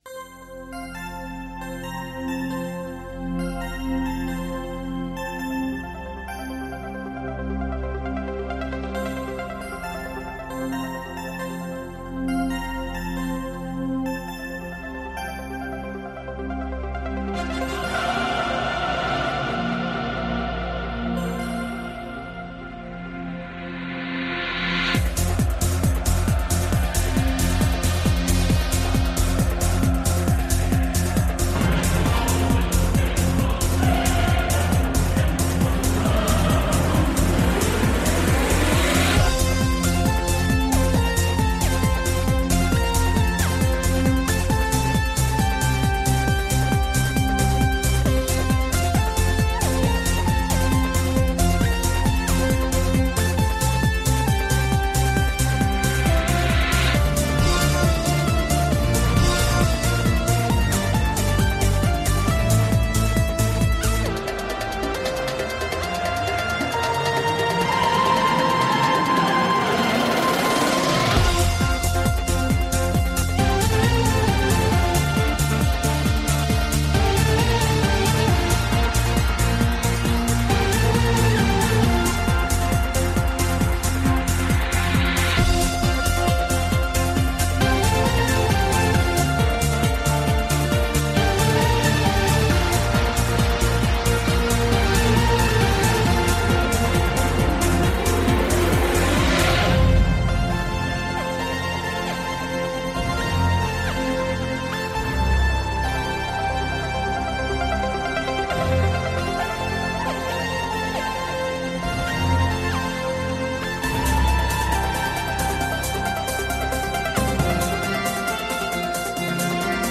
Elkarrizketa